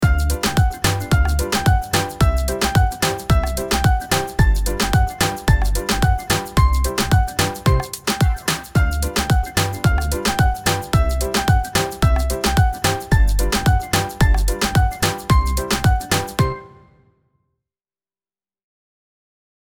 reggaeton-liszt.mp3